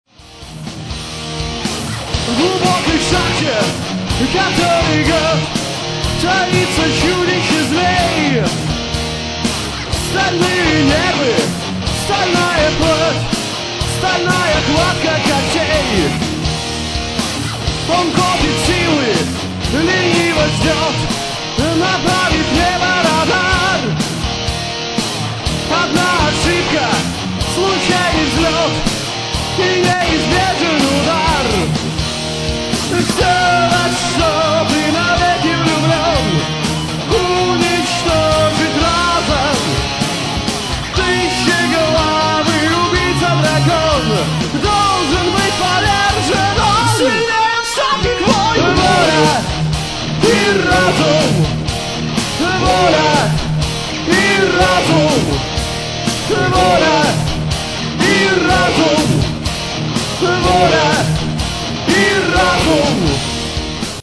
Ïî÷åìó òàê áåçæèçíåííî???
Íåïëîõîé, îòêðûòûé ãîëîñ.